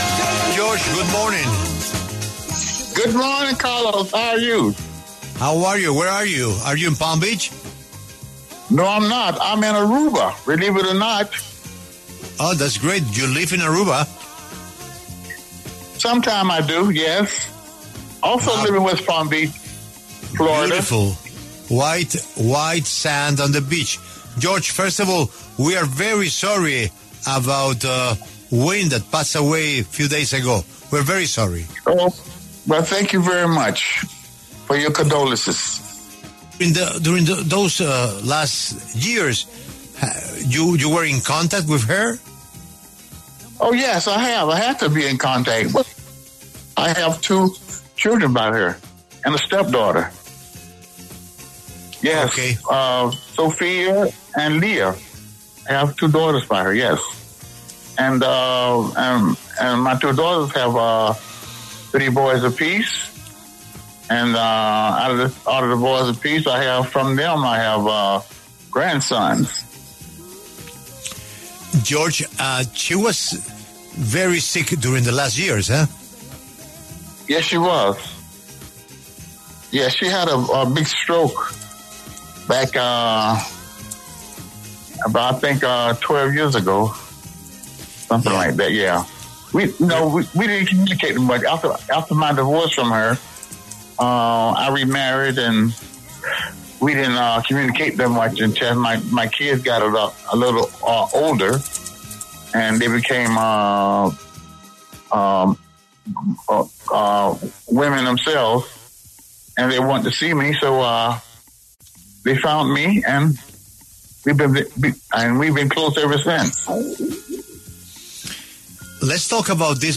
George McCrae, cantante estadounidense de soul y música disco, conocido por su éxito de 1974 ‘Rock Your Baby’, habla a propósito del aniversario número 51 de esta canción.